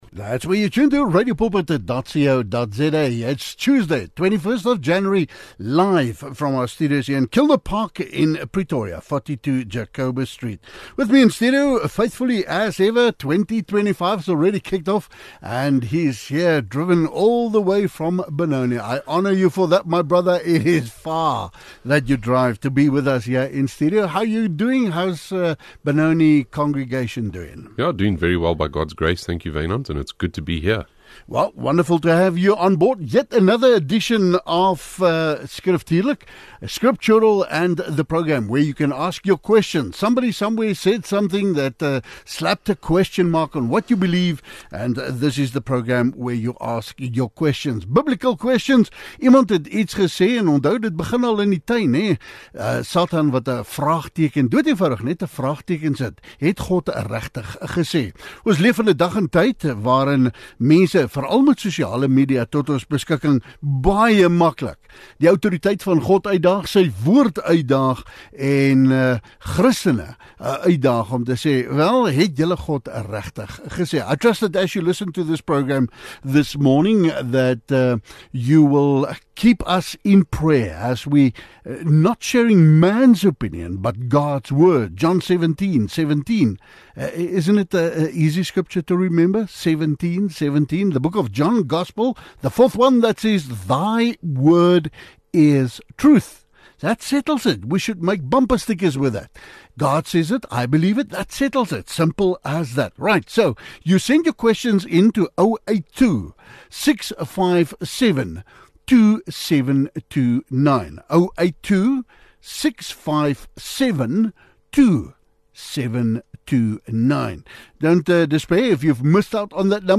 Luisteraars stuur vrae direk na die ateljee via WhatsApp.